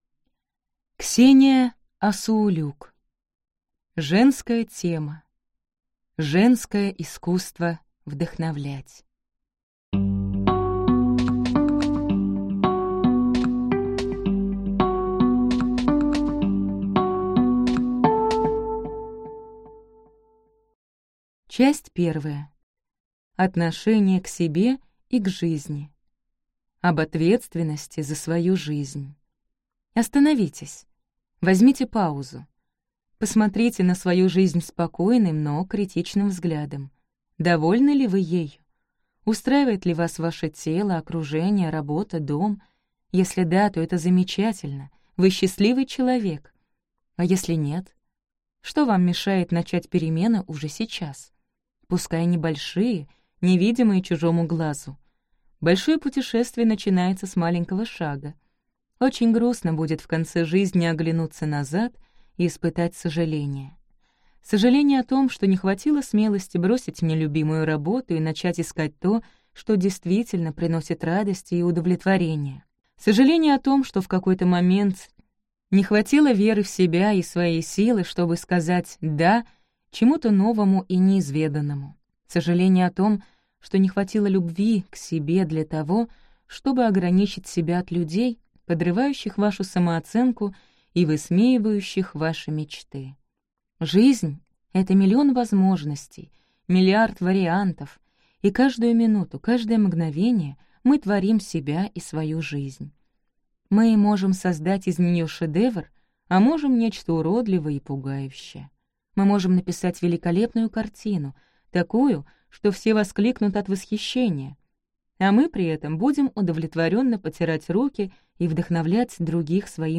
Аудиокнига Женское искусство вдохновлять | Библиотека аудиокниг